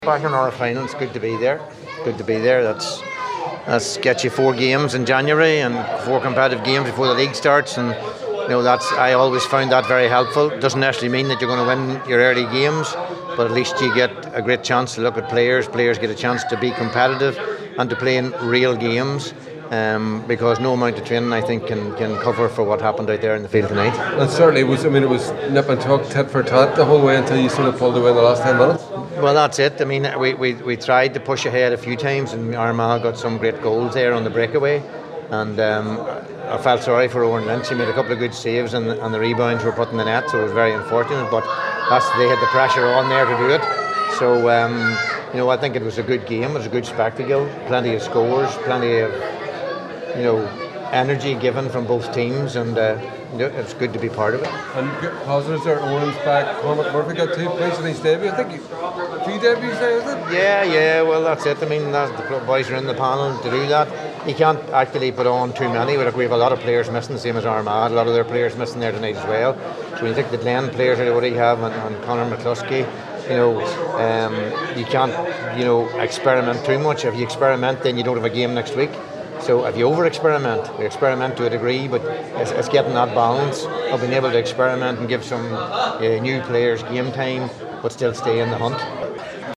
The Derry manager Mickey Harte gave his reaction when interviewed afterwards by the assembled media: